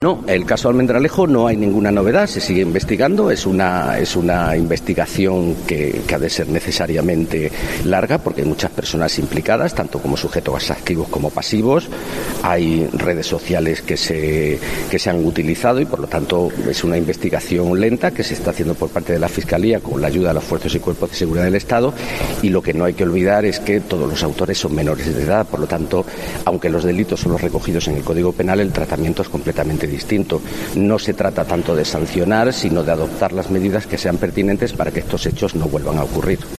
De este modo lo ha señalado el fiscal superior de Extremadura, Francisco Javier Montero Juanes, a preguntas de los medios antes de reunirse este miércoles en Mérida con la presidenta de la Asamblea, Blanca Martín, para presentarle la Memoria Anual de la Fiscalía.